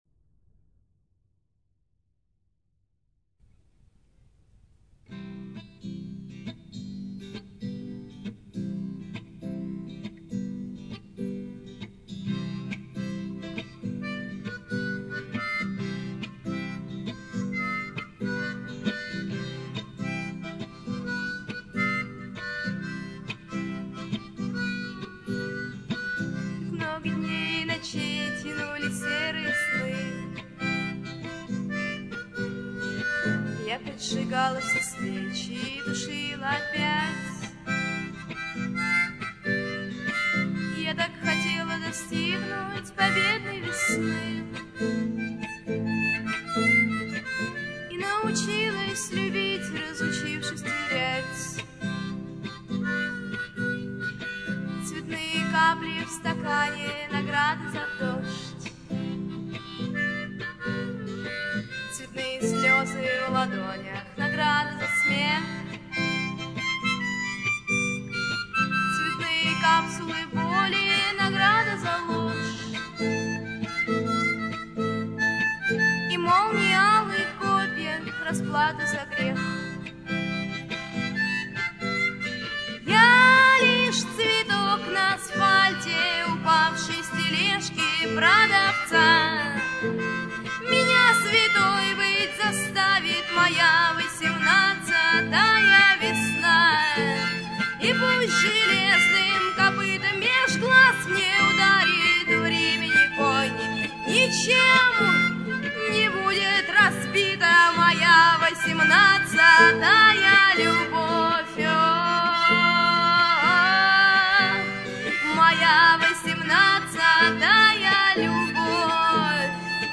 скрипка, гармошка, фортепиано